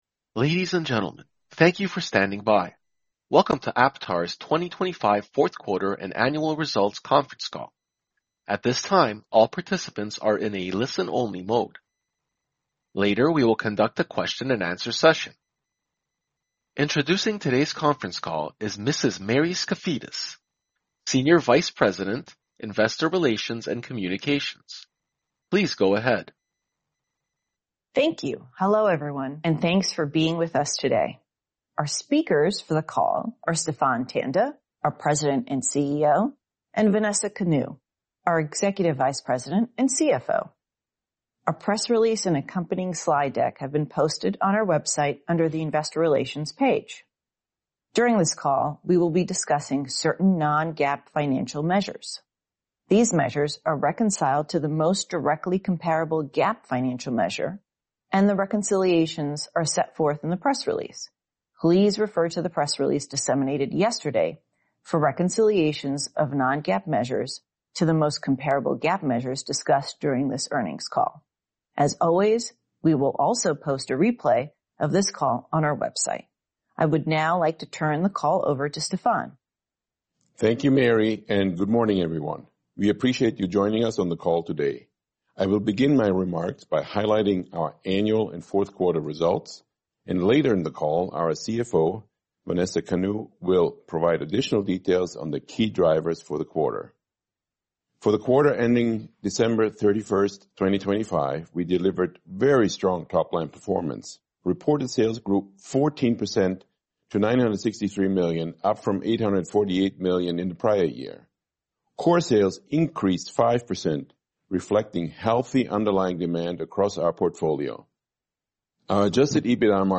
Q4 2025 Results Earnings Conference Call MP3 (opens in new window)